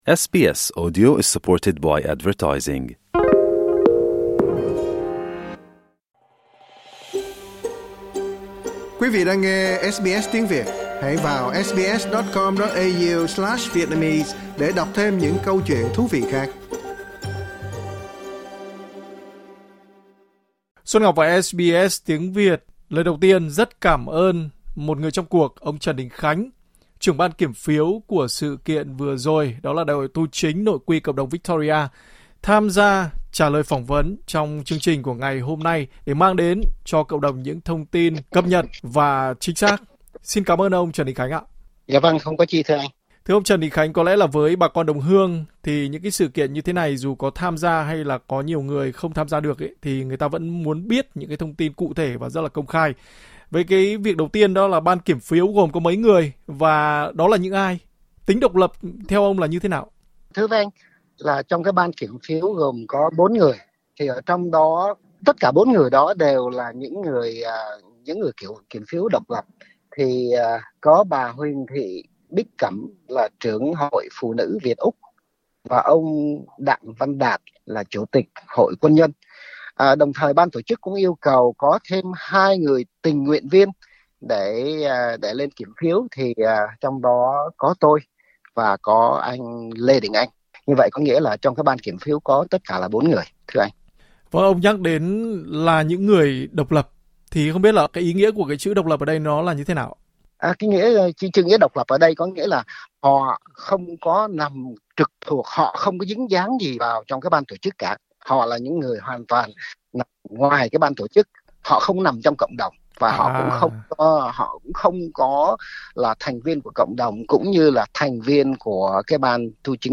Trả lời phỏng vấn SBS Tiếng Việt